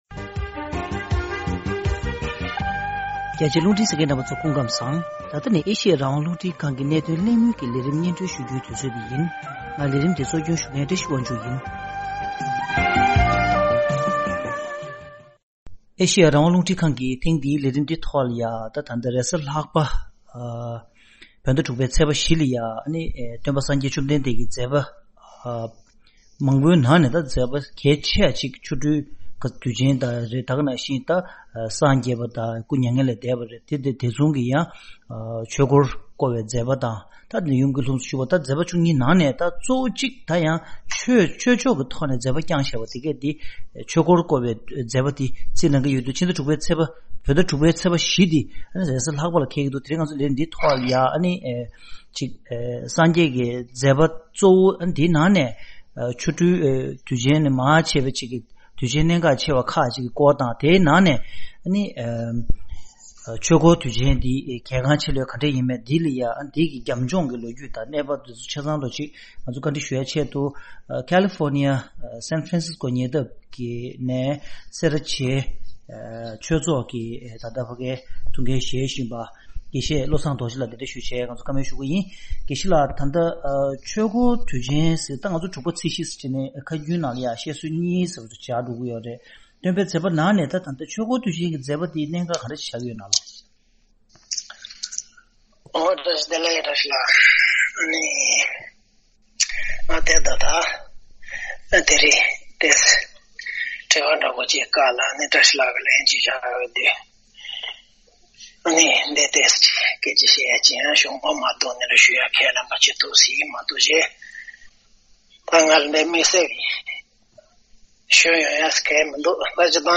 གླེང་མོལ་བཀའ་འདྲི་ཞུས་པར།